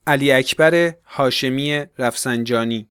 Ali_Akbar_Hashemi_Rafsanjani_from_Iran_pronunciation.ogg